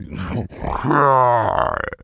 hippo_groan_02.wav